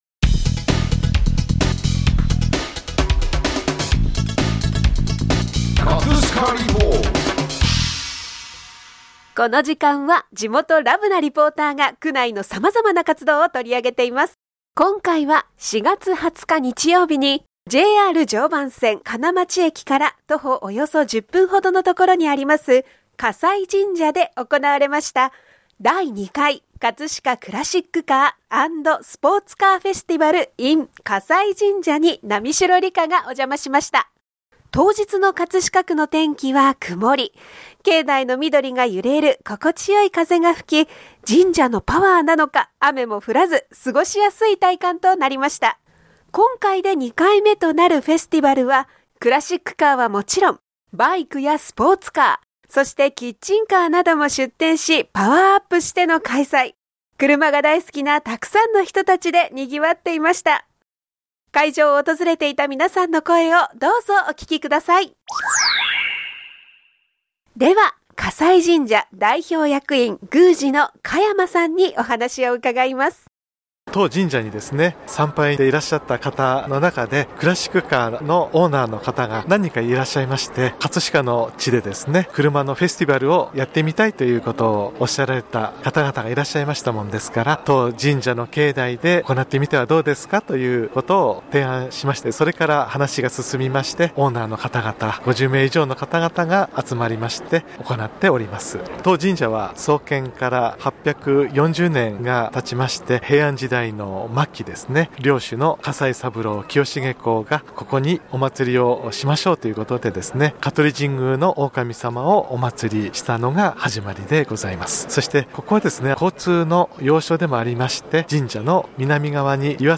【葛飾リポート】 葛飾リポートでは、区内の様々な活動を取り上げています。